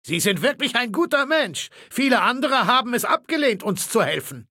Datei:Maleold01 ms06 hello 000284e7.ogg
Fallout 3: Audiodialoge